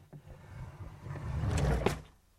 描述：抽屉滑动式关闭
Tag: 织物 嘶嘶声 金属 对象 幻灯片 沙沙